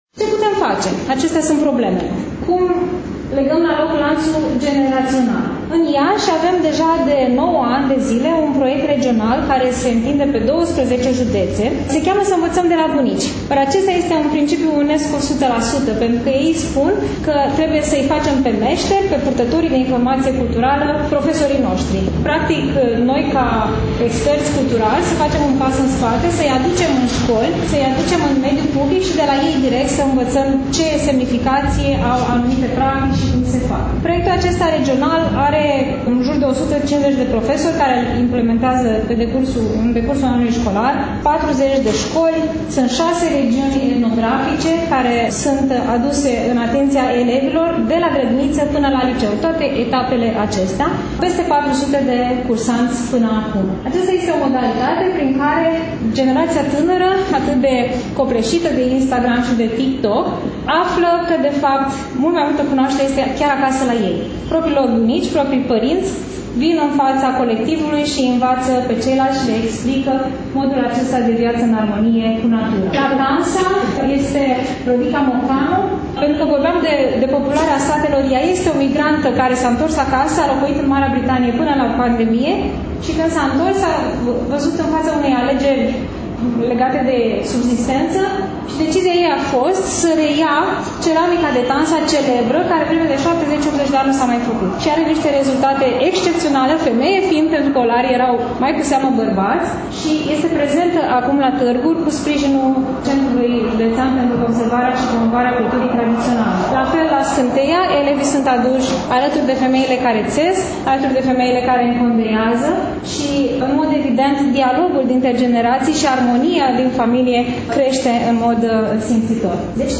Astăzi, după cum bine știți, relatăm de la expoziţia de carte „Satul românesc”, manifestare culturală desfășurată, nu demult, la Iași, în incinta Bibliotecii Centrale Universitare „Mihai Eminescu”.